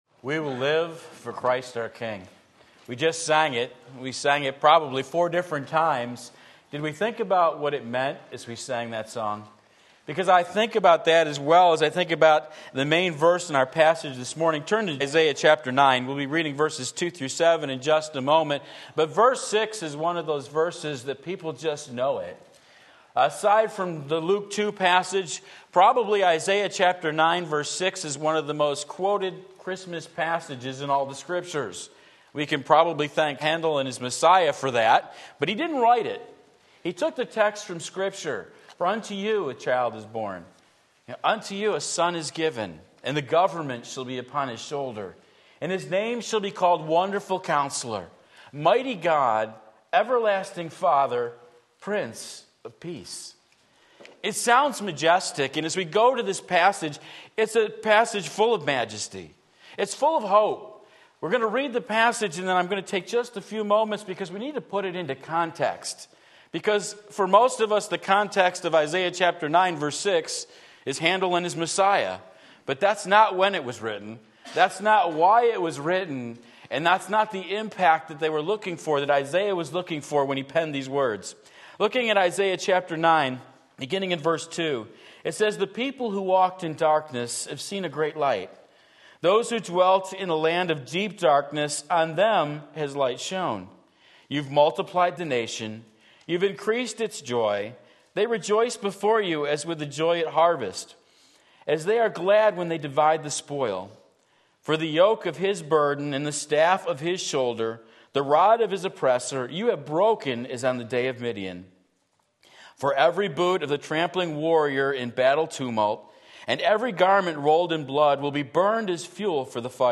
Sermon Link
To Us a Son Is Given Isaiah 9:2-7 Sunday Morning Service